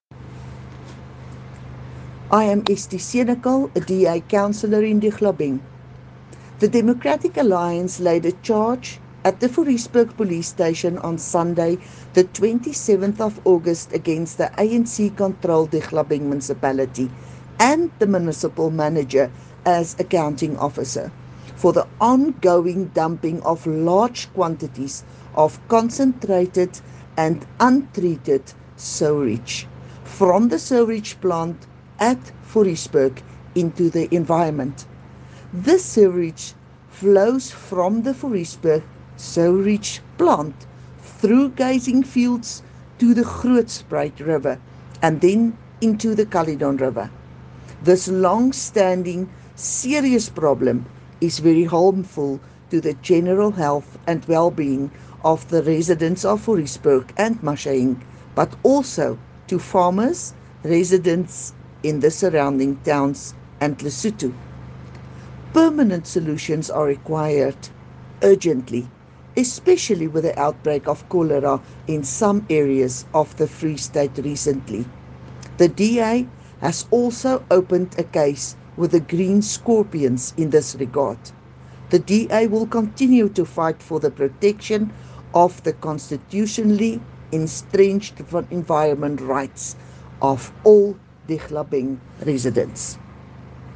Issued by Estie Senekal – DA Councillor Dihlabeng Local Municipality
Afrikaans soundbites by Cllr Estie Senekal and